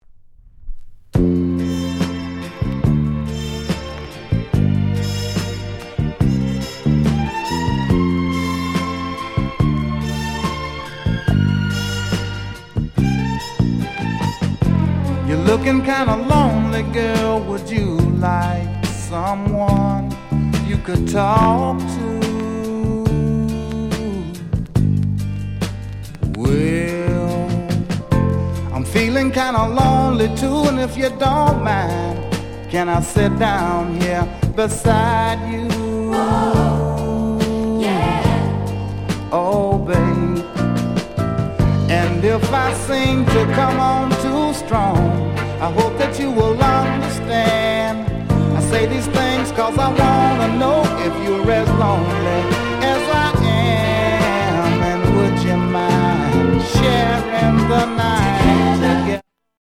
JAMAICAN SOUL